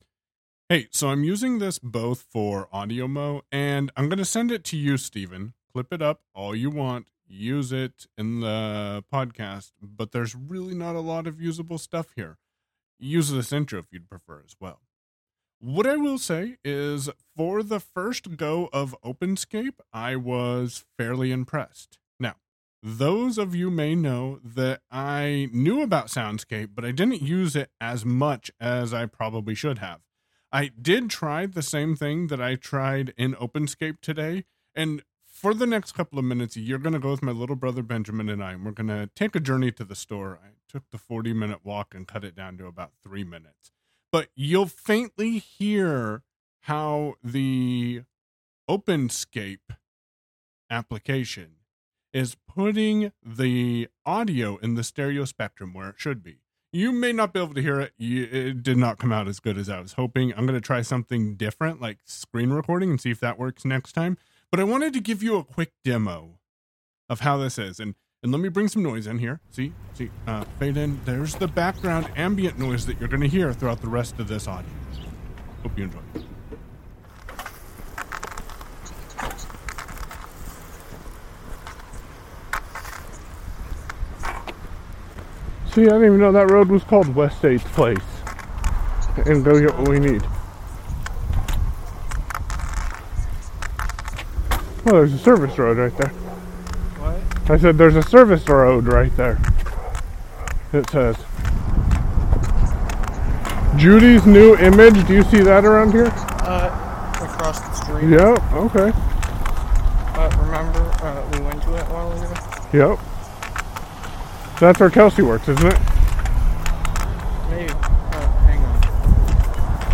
In this AudioMo I use to take a abridged journey to the store. Listen, carefully, you may hear the audio from the app, it didn't come out as cleanly as I was hoping.
Started out on a Sure Beta, 87A into the Vocaster!
ambient sounds and traveling sounds captured with the Sennheiser AMBEO binaural Mic into the iPhone. Audio fro the other phone sent threw the Shokz OpenMove.